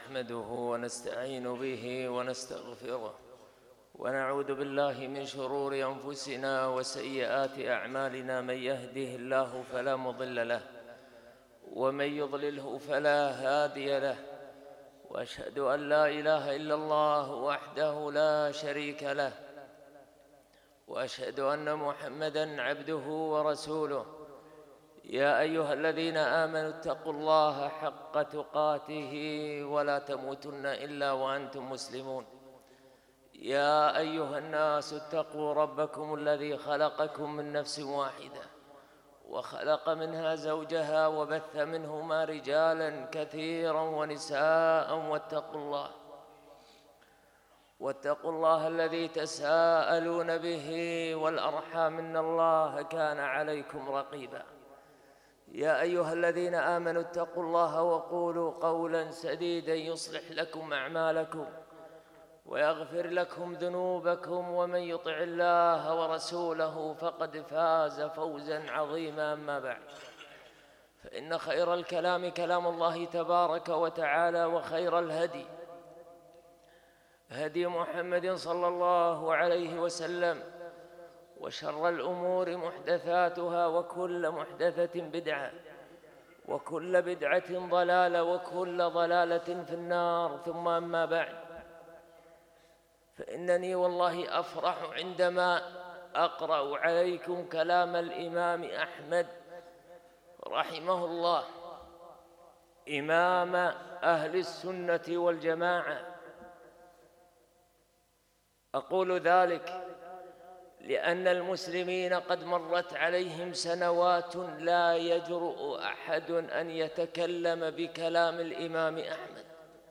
خطــب الجمــعة